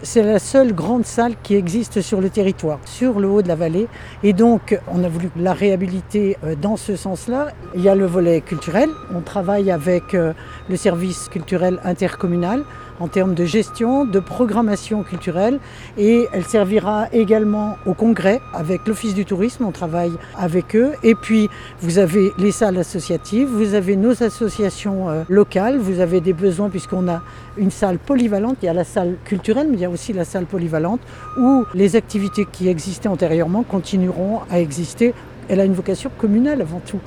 La mairie est très attentive au déroulement de ce chantier en temps voulu, car l’équipement est un point clef essentiel pour la commune et la vallée de Chamonix comme l’explique la maire des Houches Ghislaine Bossonney.